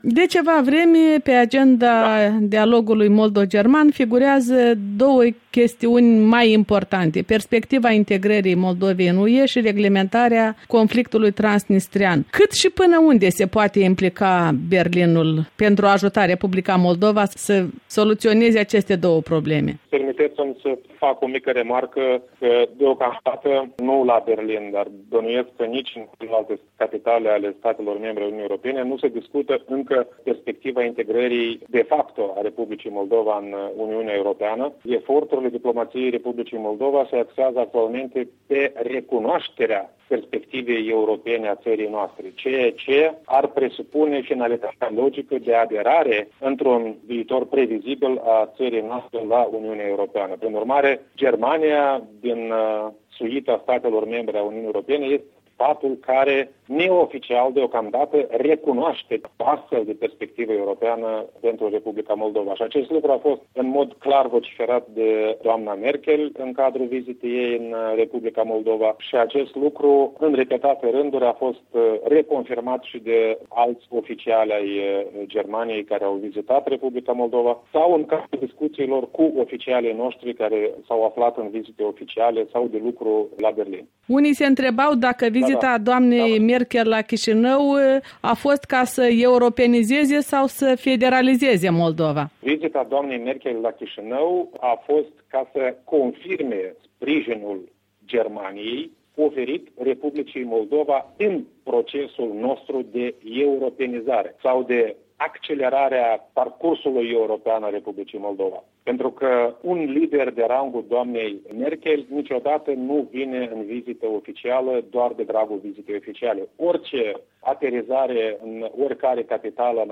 Interviu cu ambasadorul moldovean la Berlin Aurel Ciocoi